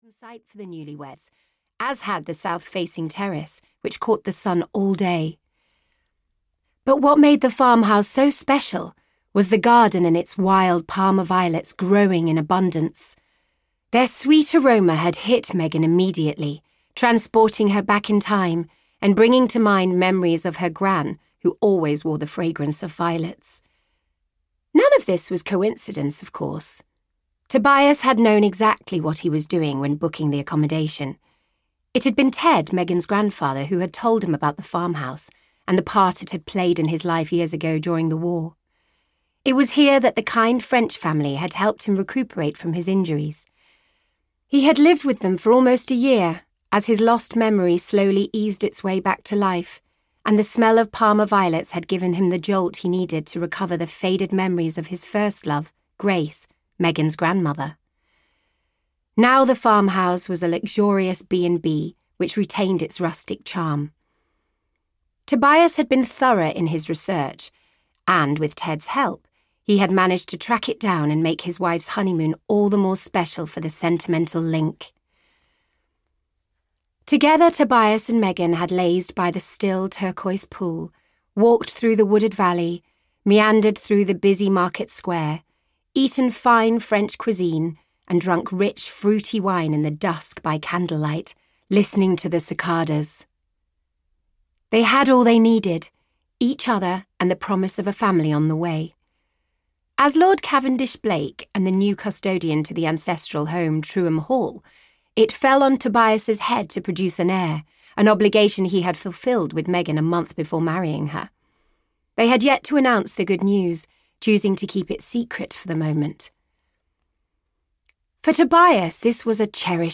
Audio knihaA Country Rivalry (EN)
Ukázka z knihy